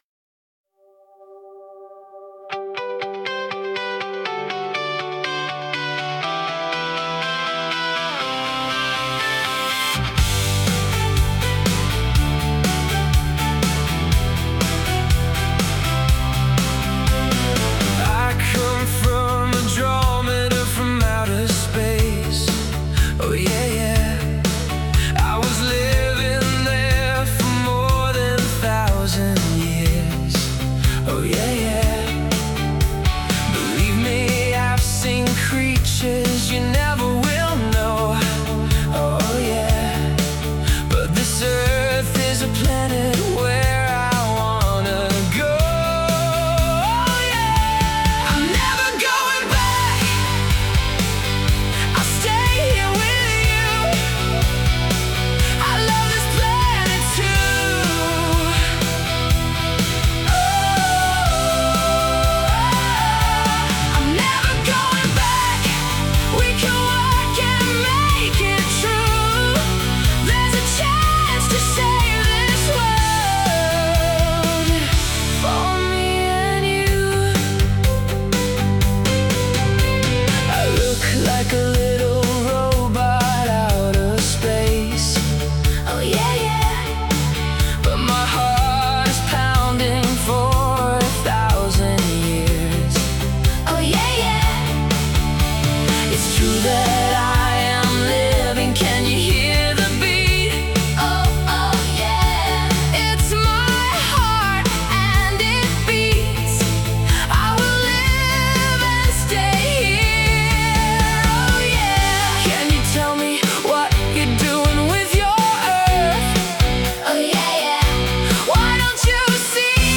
All songs on this page are Demo-Songs by EH Production